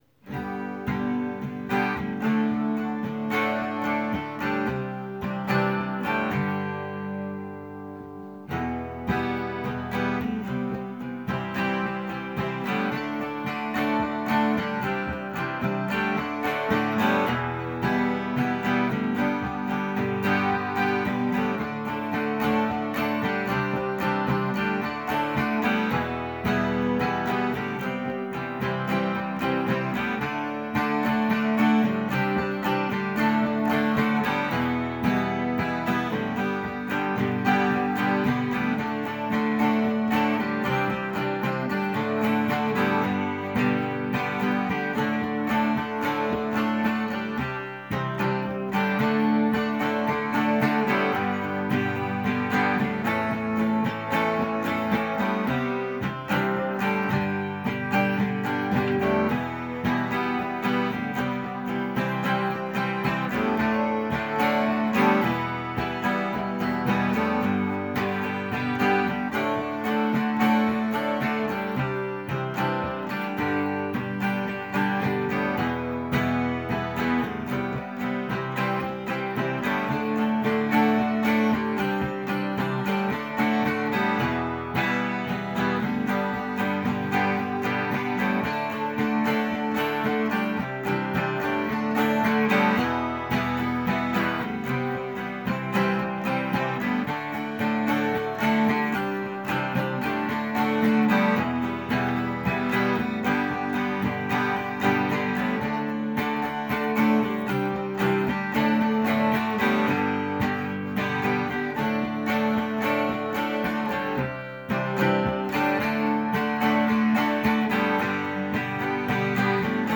music_smorgasbord_eveningoodbye_acoustic.m4a